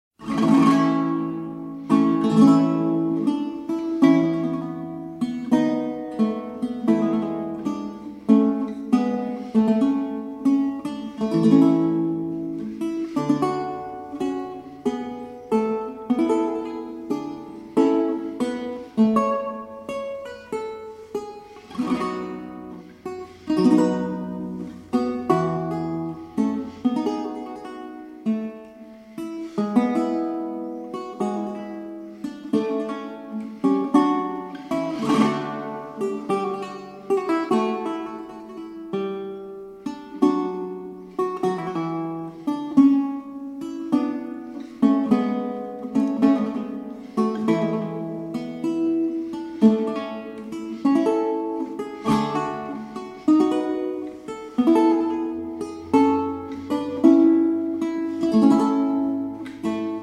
performed on baroque guitar.